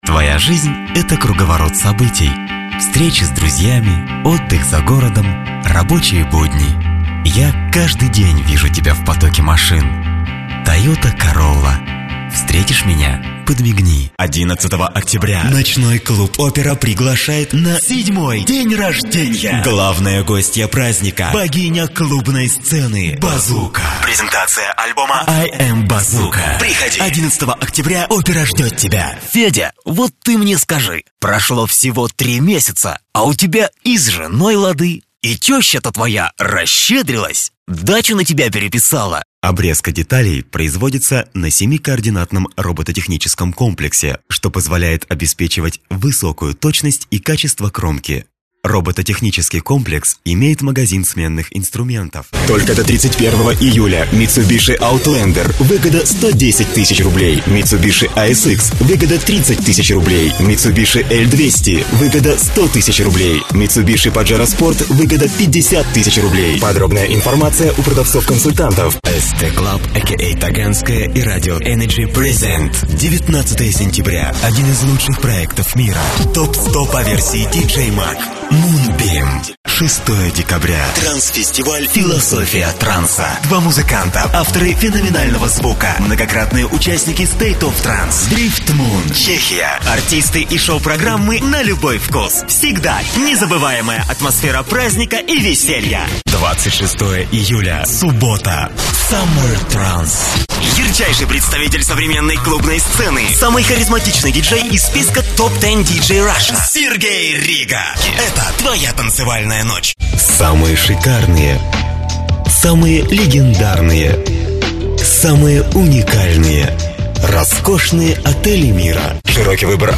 Russian Voice Over talent.
Kein Dialekt
Sprechprobe: Werbung (Muttersprache):